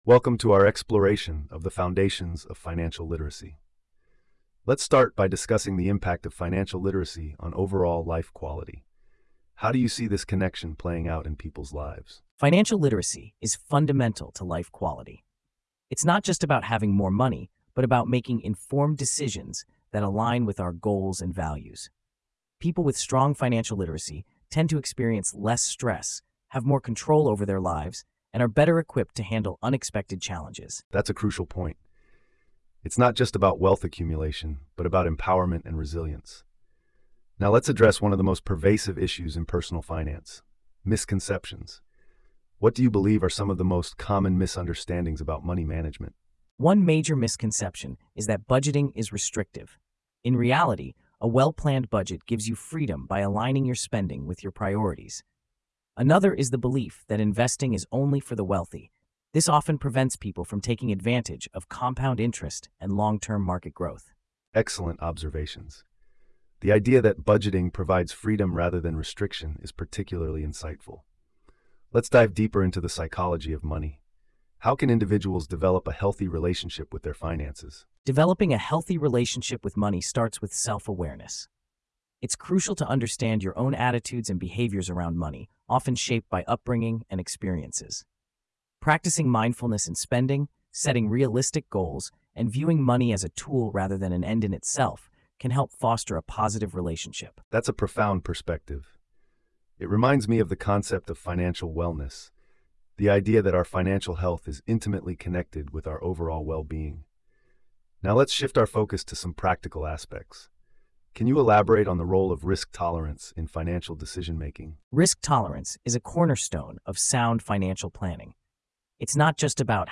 PodWaveAI - AI-Powered Podcast Generator